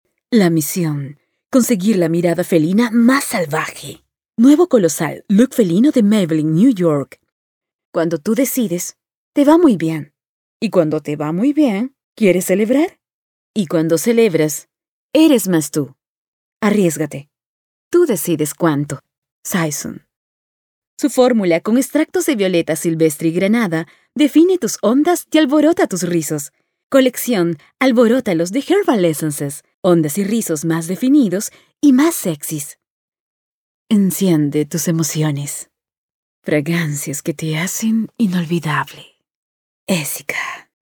Female
Approachable, Assured, Authoritative, Confident, Conversational, Cool, Corporate, Engaging, Friendly, Natural, Reassuring, Smooth, Soft, Upbeat, Versatile, Warm
Peruvian (native)
Audio equipment: Apollo x6, Soundproof and acoustically treated recording booth